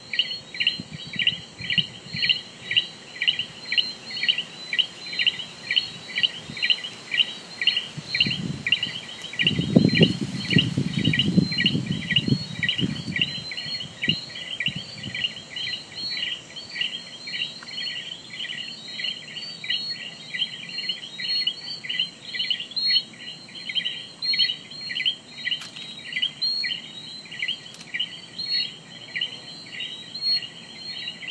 Night sounds on St. John.